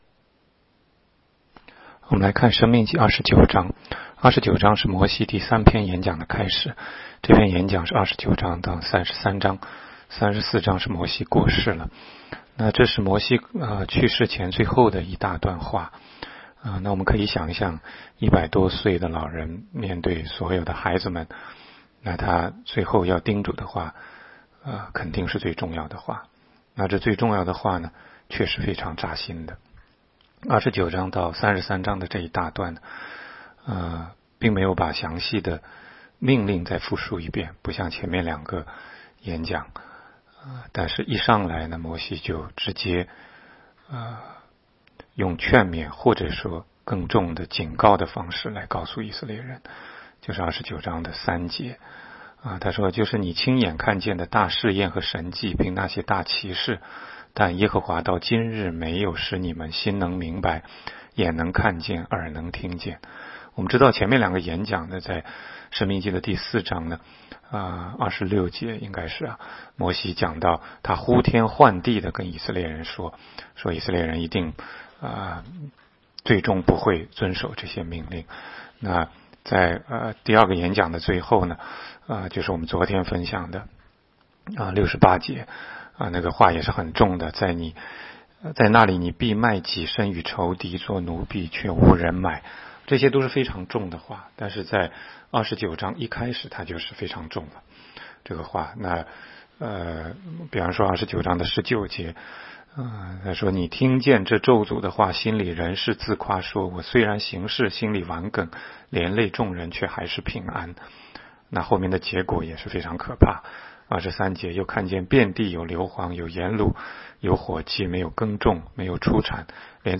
16街讲道录音 - 每日读经-《申命记》29章
每日读经